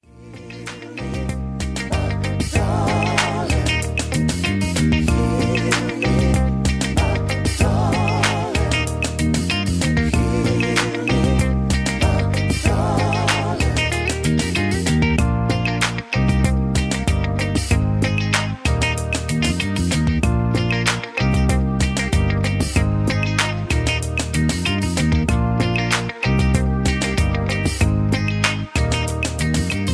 karaoke mp3s , backing tracks